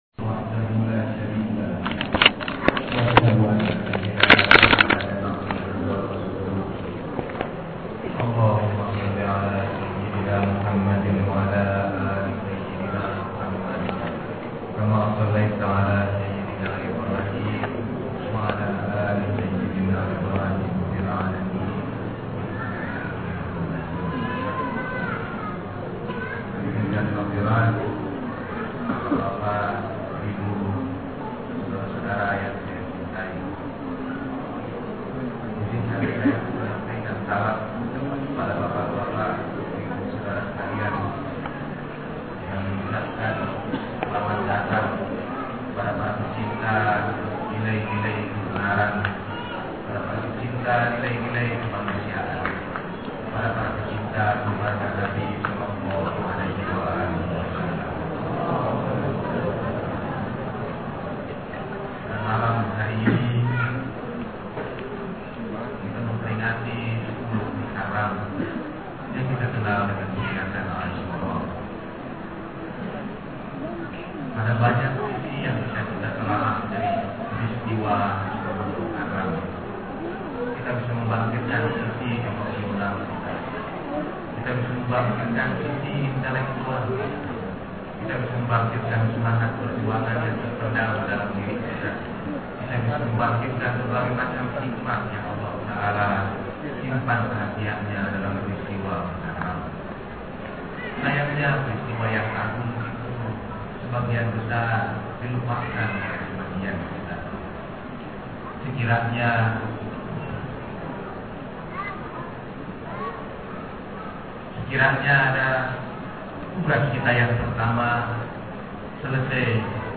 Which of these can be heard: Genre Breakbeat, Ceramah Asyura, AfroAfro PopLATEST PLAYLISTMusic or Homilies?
Ceramah Asyura